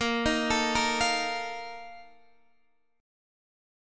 A#M7sus4 chord